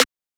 juugsnare3.wav